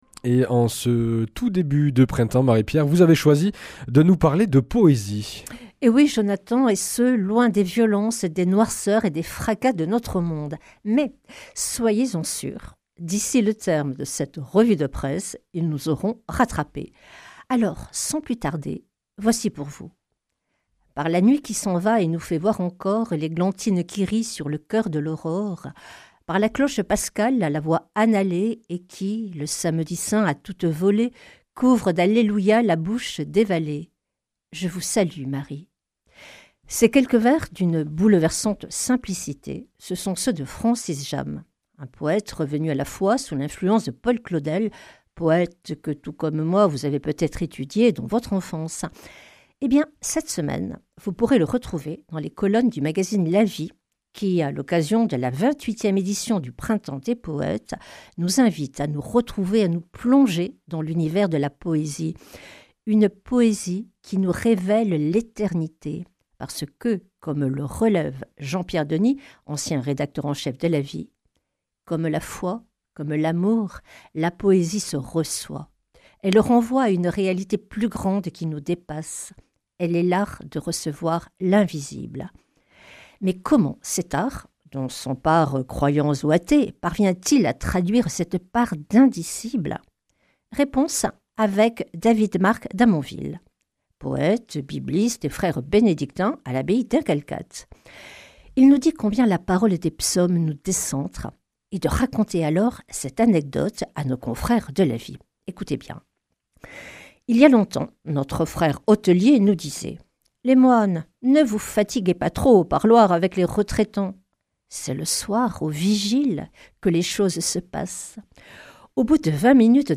Revue de presse